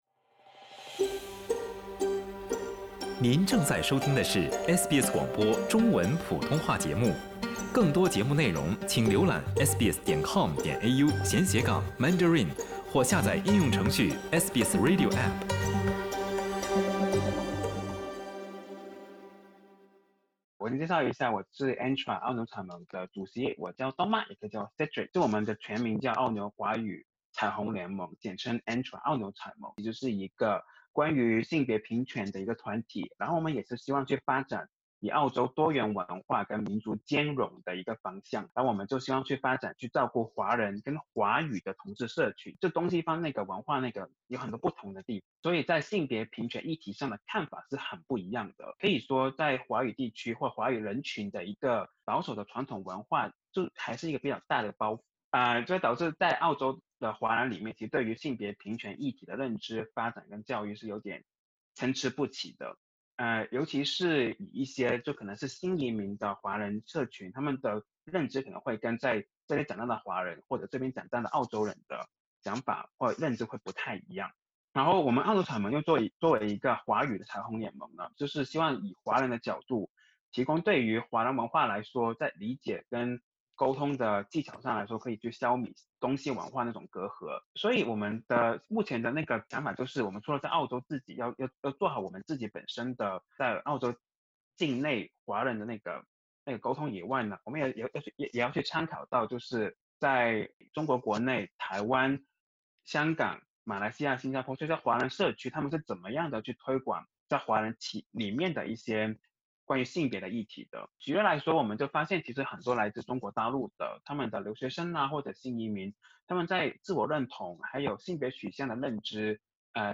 澳洲华人性别平权组织如何走出澳洲、连结起世界各地华人同志团体？ANTRA澳纽彩盟的经验与您分享。点击首图收听完整采访音频。